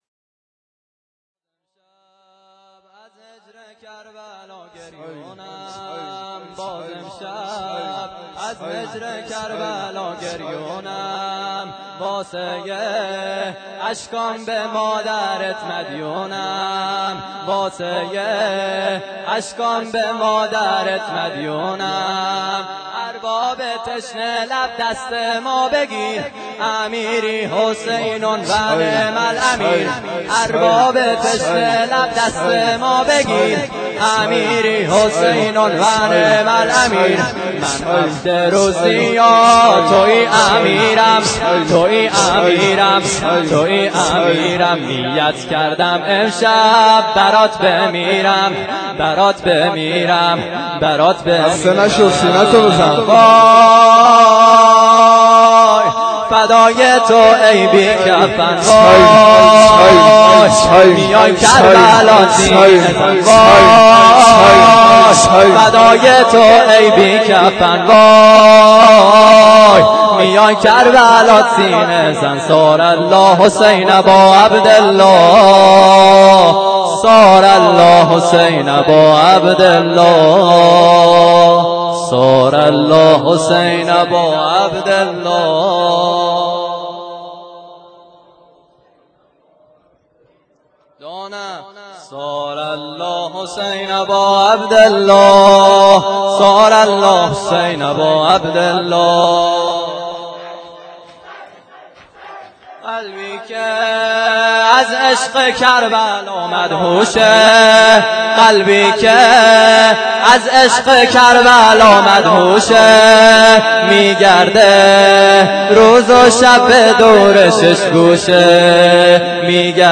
ارباب تشنه لب دست ما بگیر _ شور